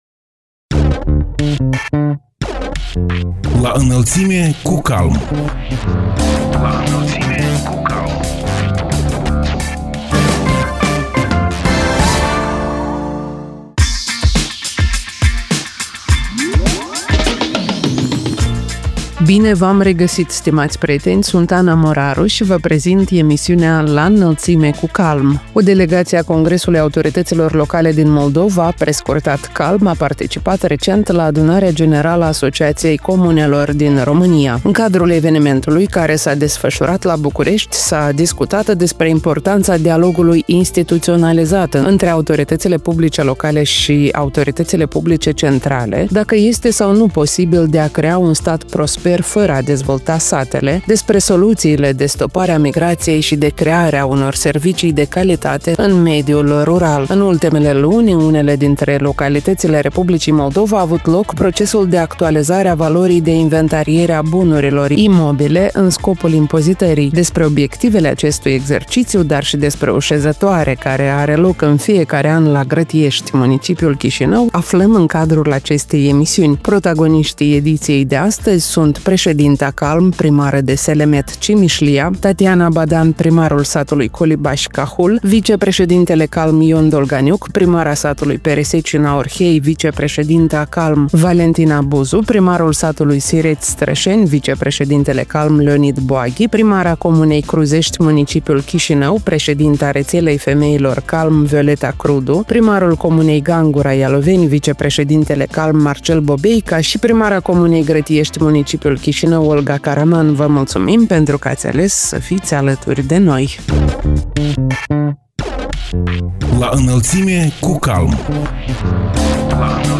Protagoniștii ediției sunt: președinta CALM, primară de Selemet, Cimișlia, Tatiana Badan; primarul satului Colibași, Cahul, vicepreședintele CALM, Ion Dolganiuc; primara satului Peresecina, Orhei, vicepreședintă a CALM, Valentina Buzu; primarul satului Sireți, Strășeni, vicepreședintele CALM, Leonid Boaghi; primara comunei Cruzești, municipiul Chișinău, președinta Rețelei Femeilor CALM, Violeta Crudu; primarul comunei Gangura, Ialoveni, vicepreședintele CALM, Marcel Bobeica și primara comunei Grătiești, municipiul Chișinău, Olga Caraman.